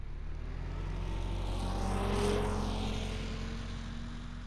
Internal Combustion Snowmobile Description Form (PDF)
Internal Combustion Subjective Noise Event Audio File (WAV)